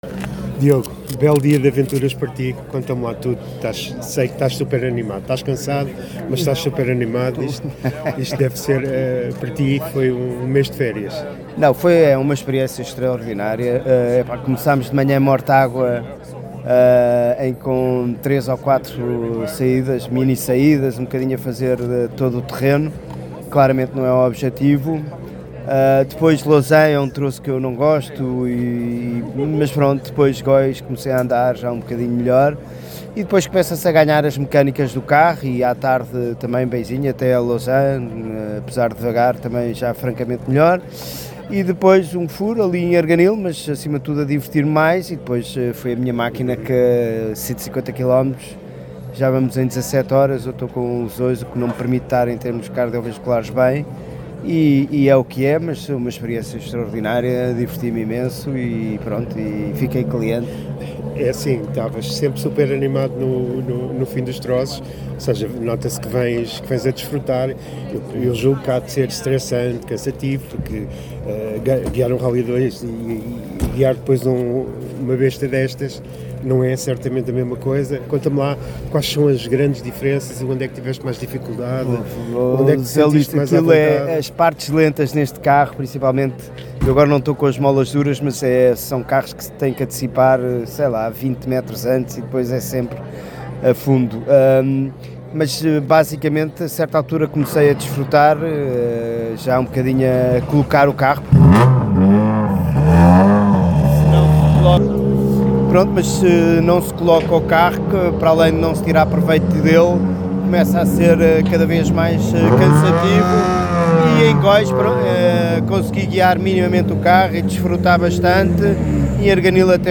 No final do dia, em conversa com o AutoSport, fez o balanço desta odisseia pelo centro do país, por troços belos, duros e exigentes.